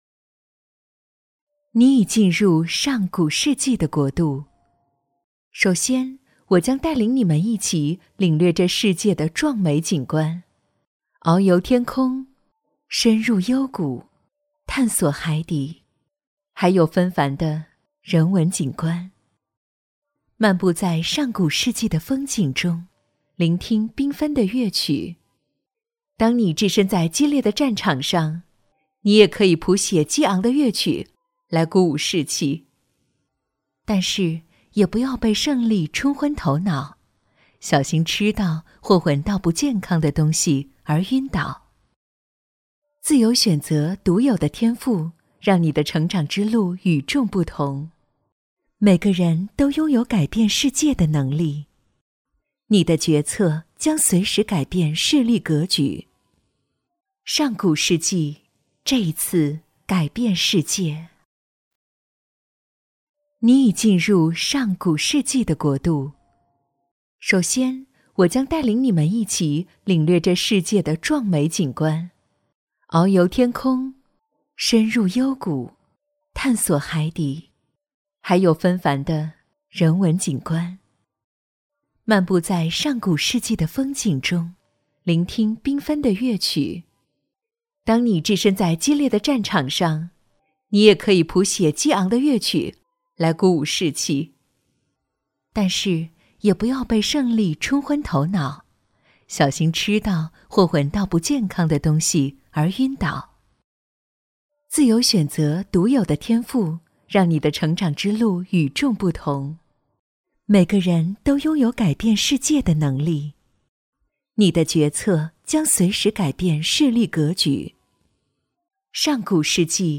• 女S12 国语 女声 角色-上古世纪-游戏类配音-优雅 沉稳|娓娓道来|神秘性感|亲切甜美